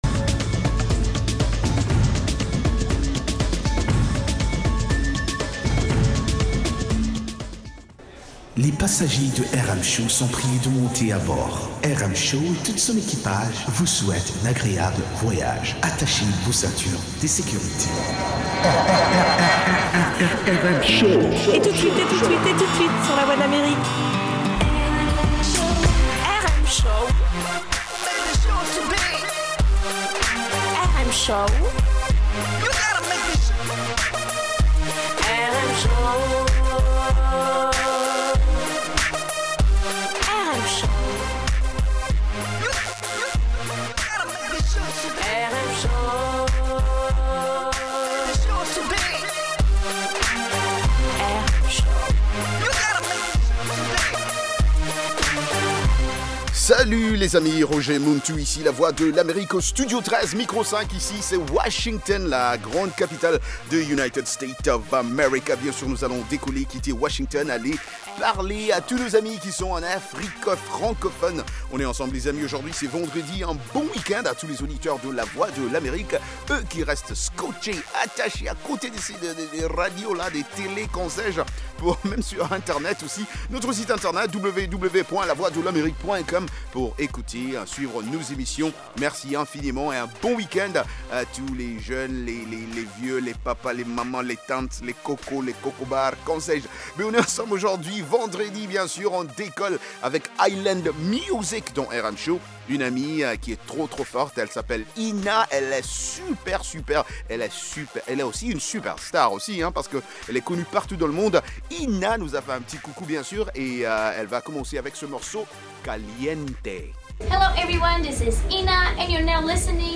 Ecoutez toute la musique des îles, Zouk, Reggae, Latino, Soca, Compas et Afro, et interviews de divers artistes